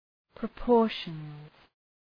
{prə’pɔ:rʃənz}